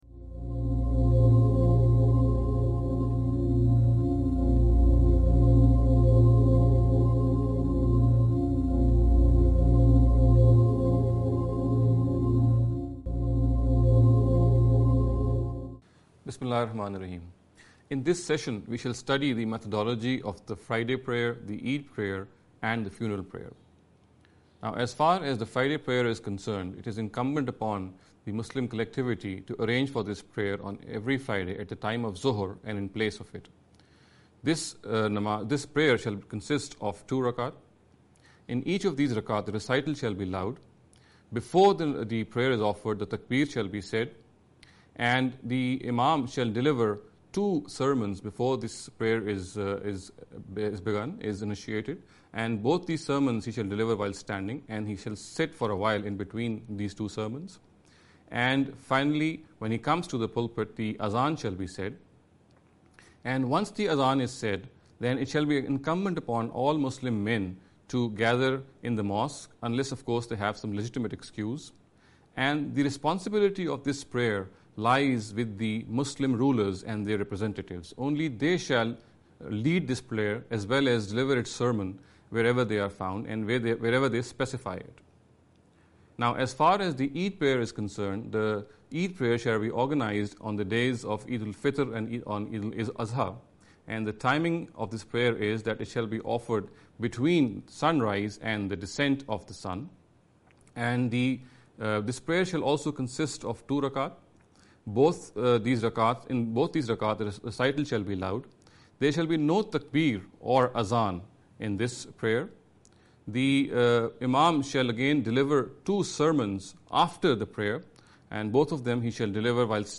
This lecture series will deal with some misconception regarding the Understanding The Qur’an. In every lecture he will be dealing with a question in a short and very concise manner. This sitting is an attempt to deal with the question 'Friday, Eid & Funeral Prayers’.